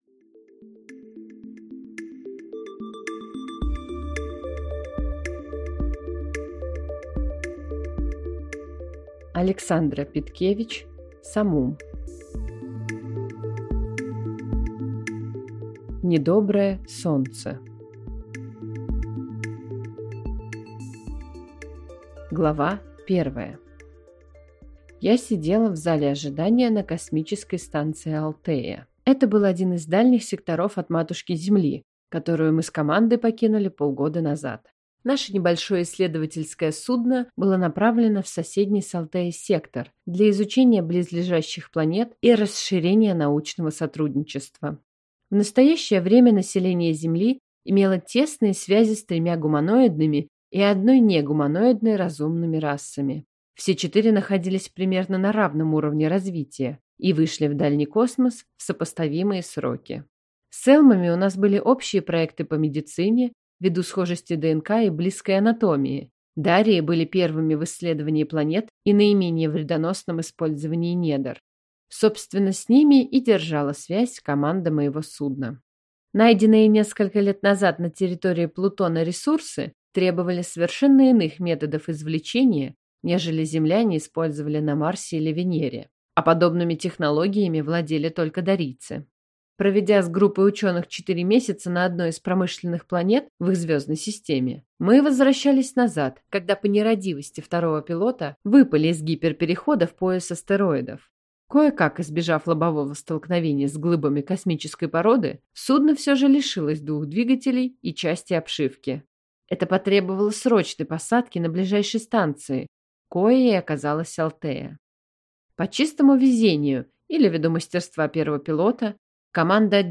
Аудиокнига Недоброе солнце | Библиотека аудиокниг